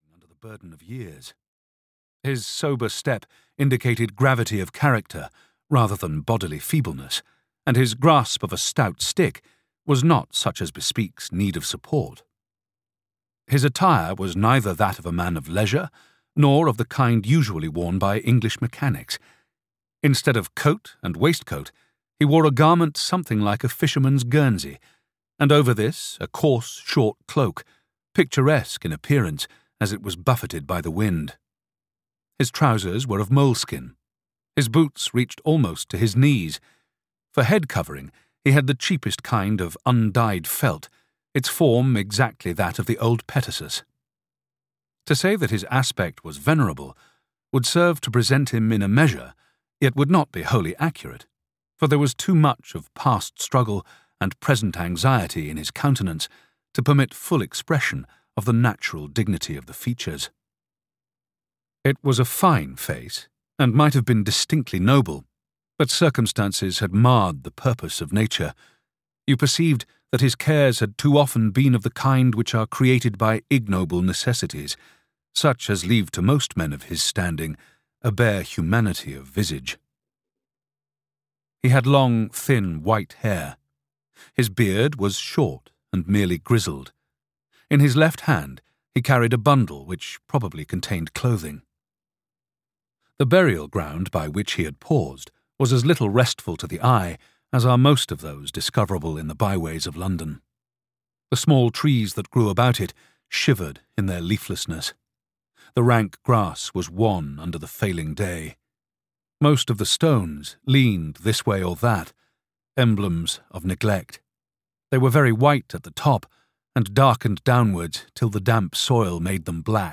The Nether World (EN) audiokniha
Ukázka z knihy